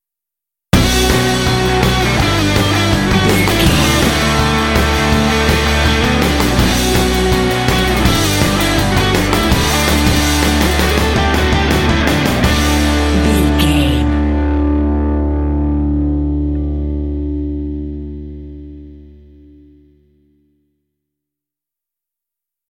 Ionian/Major
Fast
driving
energetic
lively
electric guitar
drums
bass guitar
classic rock
alternative rock